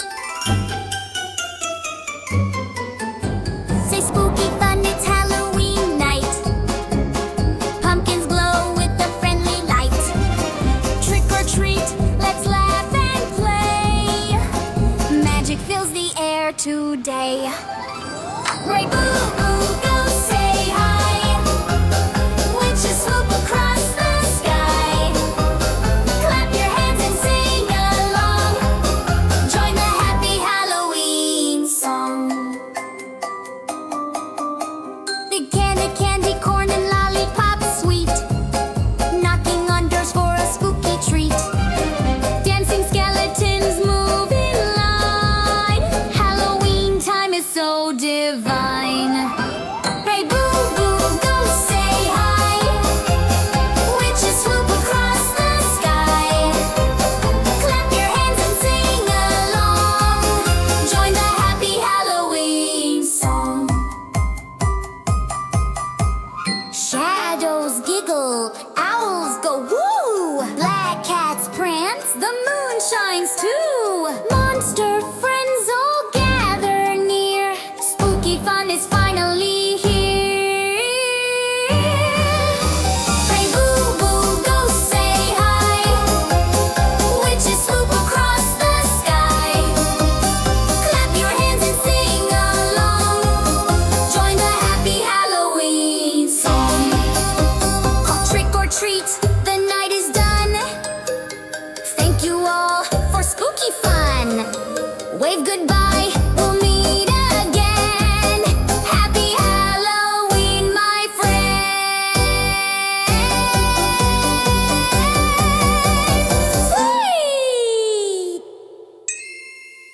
A playful and magical Halloween music pack for kids!